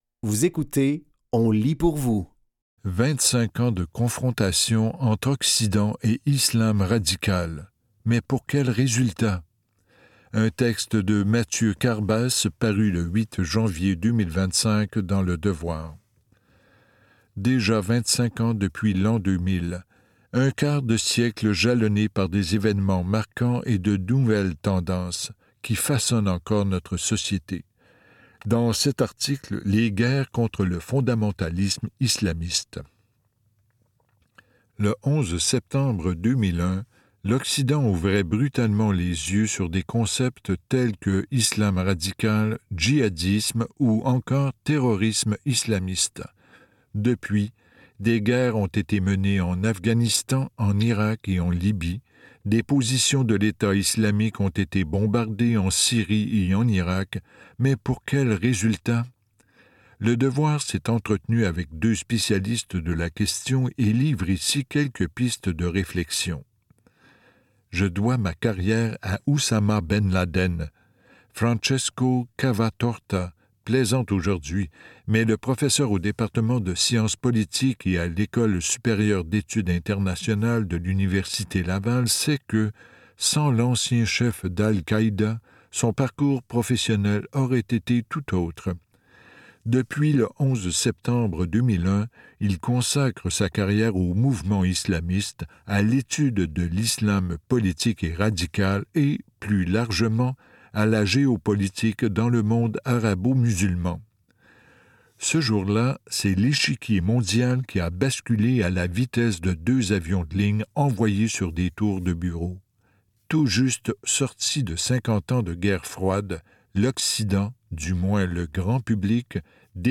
Dans cet épisode de On lit pour vous, nous vous offrons une sélection de textes tirés des médias suivants : Le Devoir, La Gazette des Femmes, Espaces Autochtones.